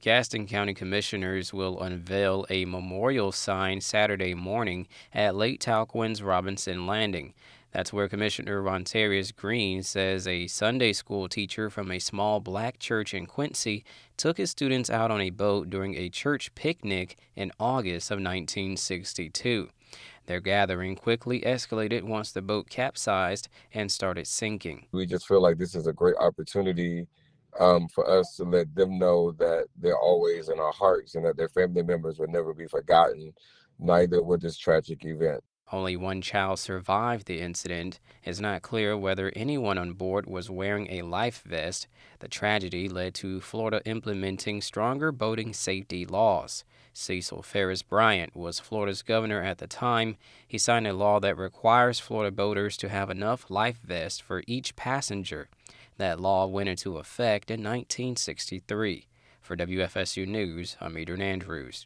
BROADCAST TRANSCRIPT: